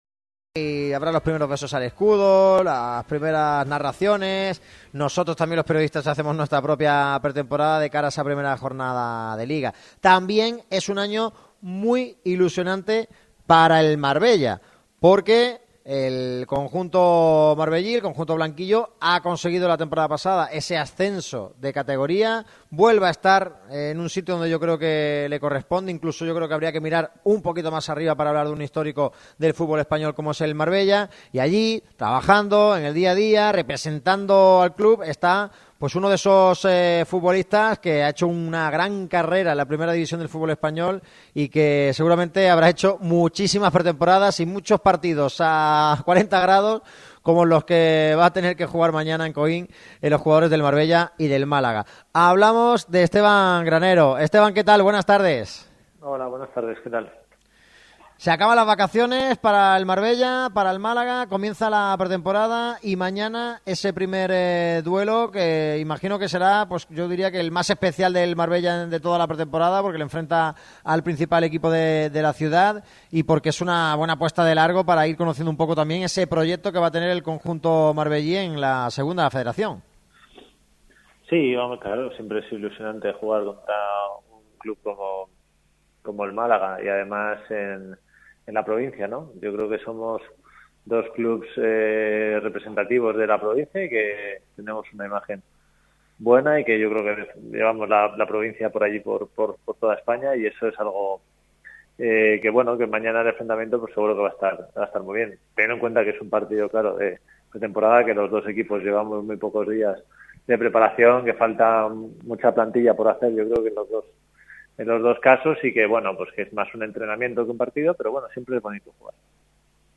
El ex futbolista ha atendido a Radio MARCA Málaga en la previa del partido.